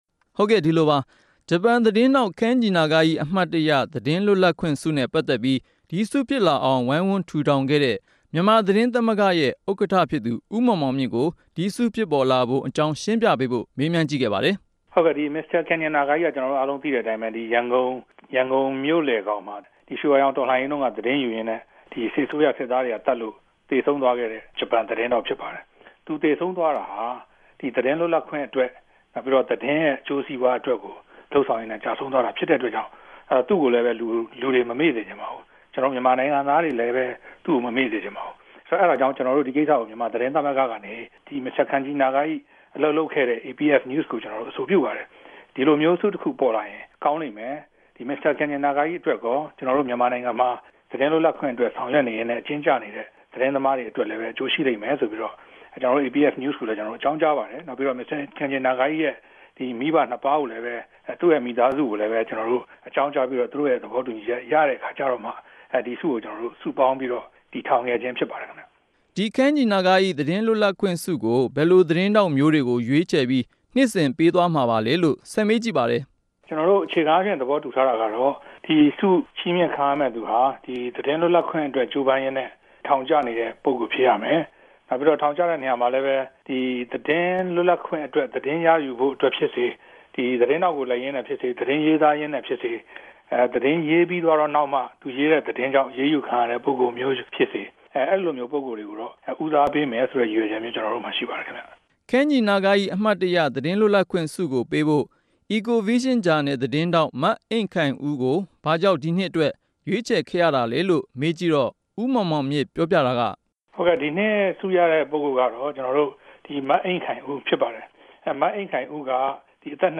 သတင်းပေးပိုႛခဵက်။